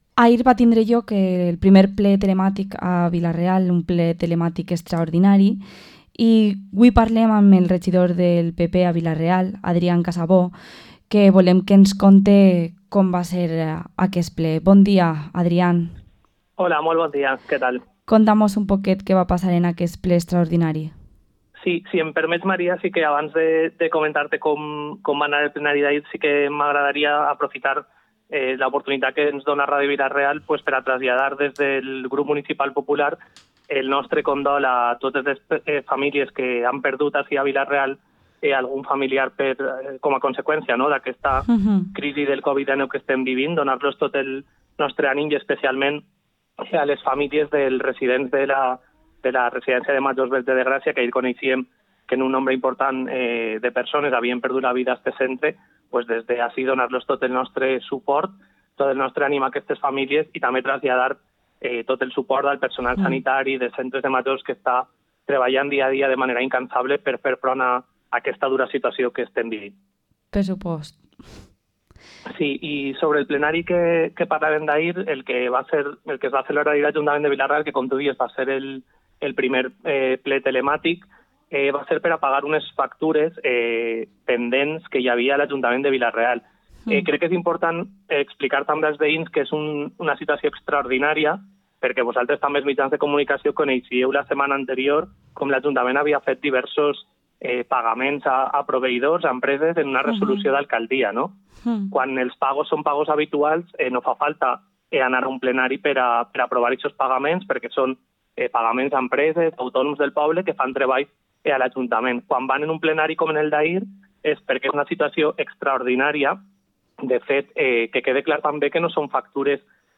Entrevista al concejal del PP en Vila-real, Adrián Casabó